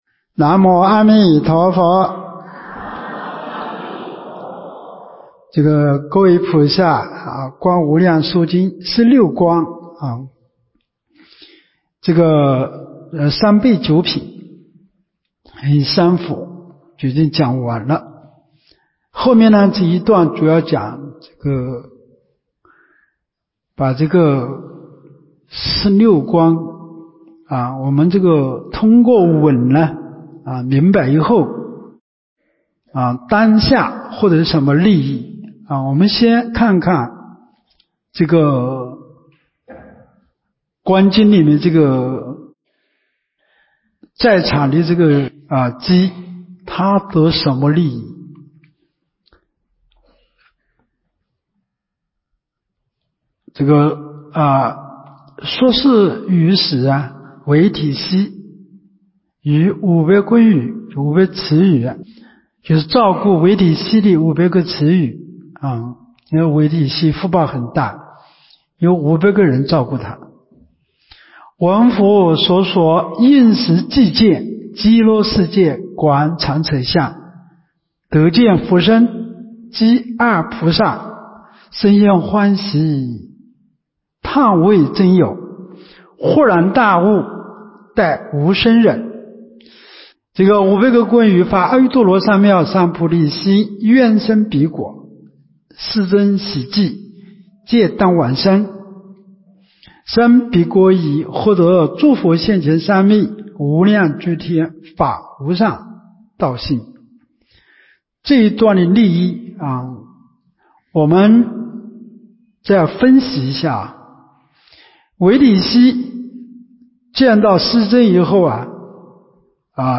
24年陆丰学佛苑冬季佛七（十）